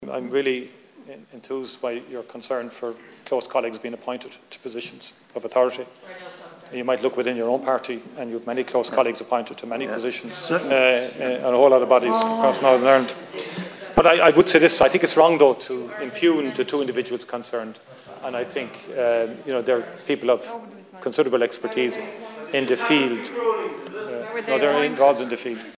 A Donegal Deputy has clashed in the Dail with Taoiseach Michael Martin after the Environment Minister Eamon Ryan appointed ‘two close colleagues’ as members of the Climate Change Advisory Council.
Responding, The Taoiseach says he was enthused by his ‘concern’: